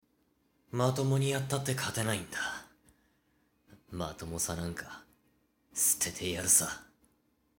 男性
SampleVoice01